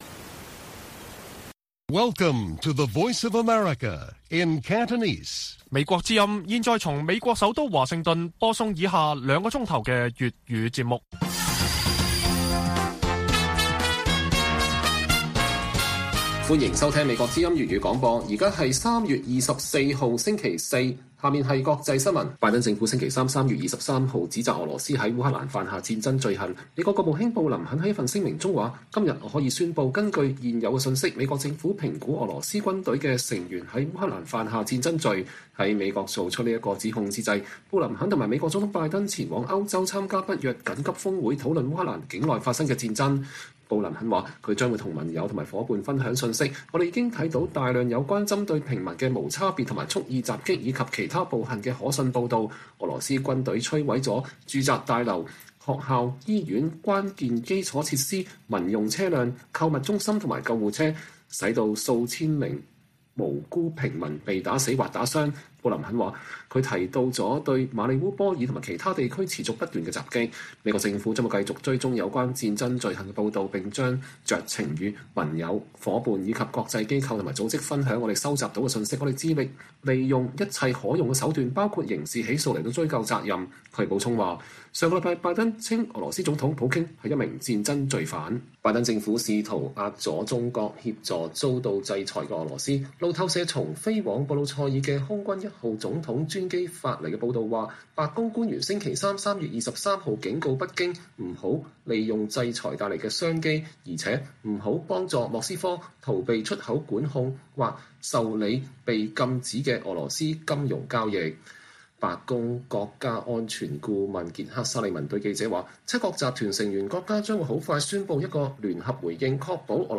粵語新聞 晚上9-10點: 美國指控俄羅斯在烏克蘭犯下戰爭罪